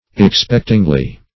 Meaning of expectingly. expectingly synonyms, pronunciation, spelling and more from Free Dictionary.
expectingly - definition of expectingly - synonyms, pronunciation, spelling from Free Dictionary Search Result for " expectingly" : The Collaborative International Dictionary of English v.0.48: Expectingly \Ex*pect"ing*ly\, adv. In a state of expectation.